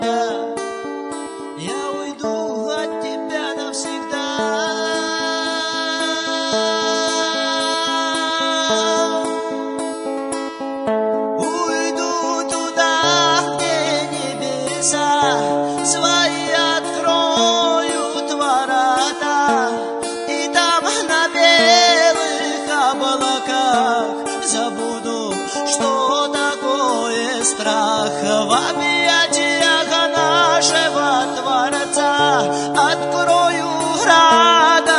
Жанр: Русские
Chanson in Russian